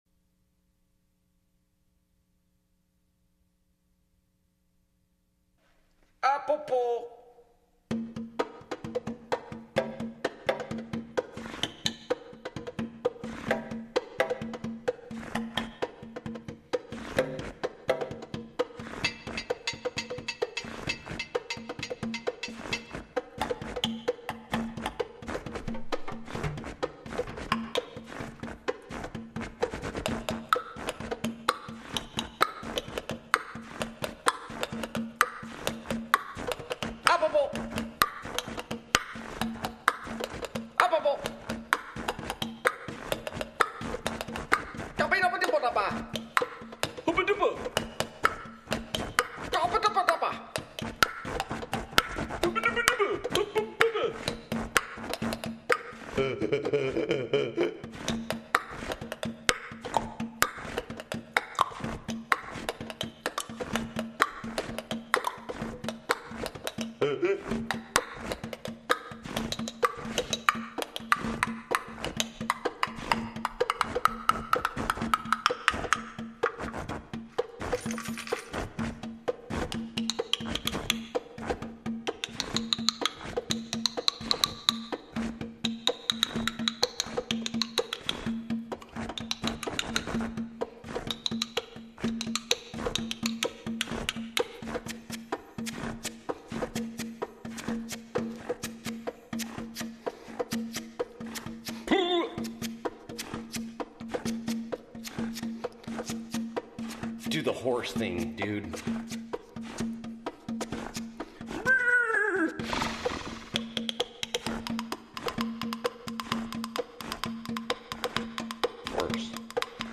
lead vocals
lead guitar, oboe, sax, pan flute
bass & keyboards
drums & vocals